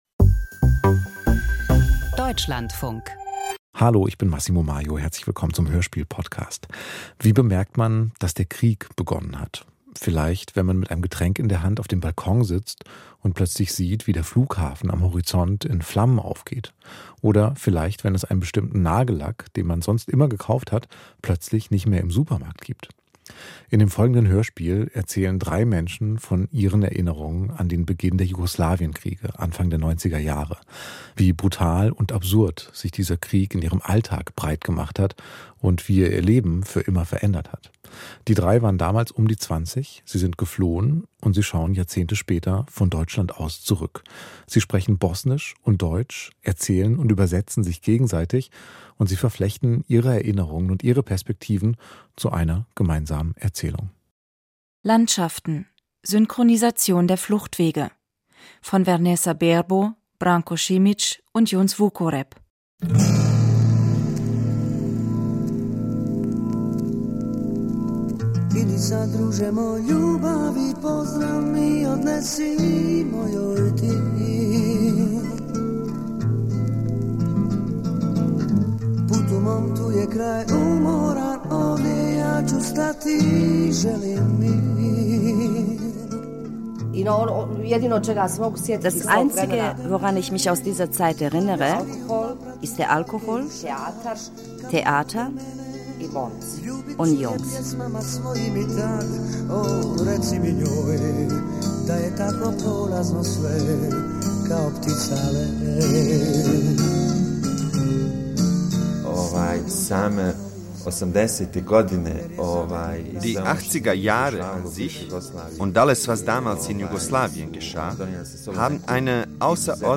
Hörspiel über den Schriftsteller Gerhard Meier - Das Ohr der Welt in Meiers Garten
Experten und Wegbegleiter erinnern sich an den Schweizer Schriftsteller Gerhard Meier.